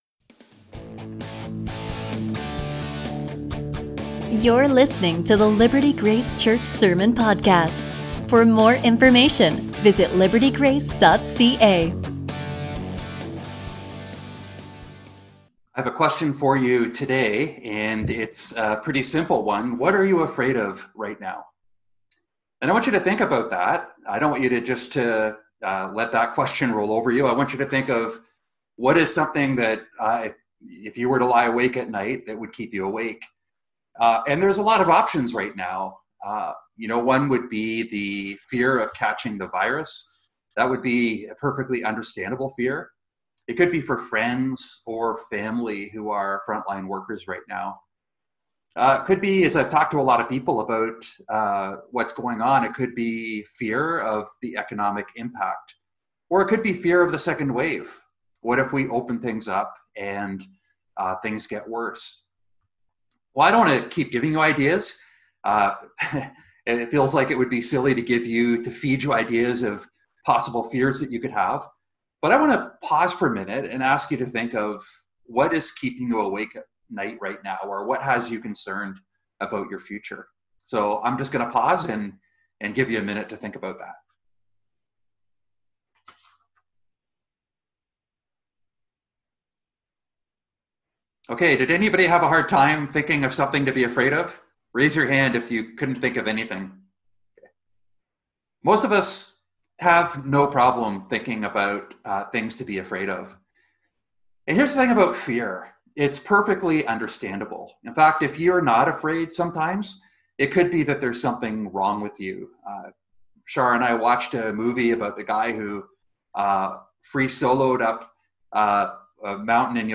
A message from the series "Lockdown."